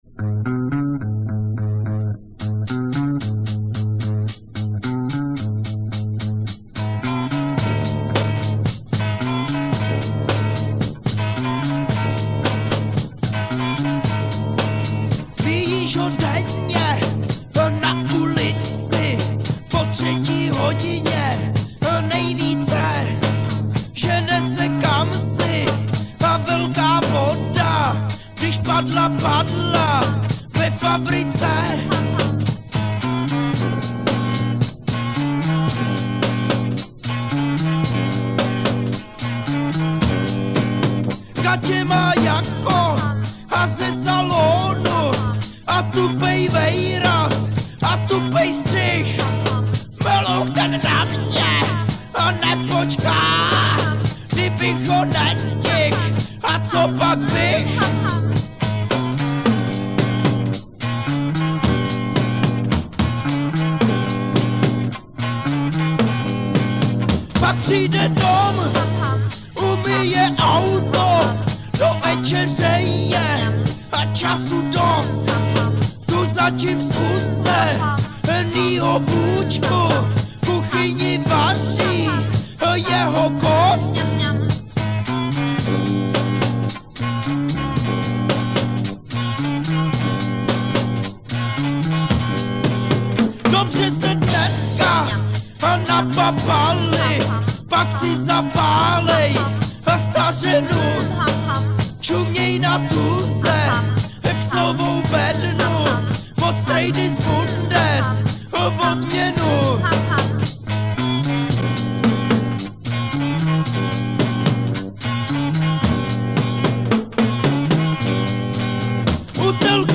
voc, g
fl, voc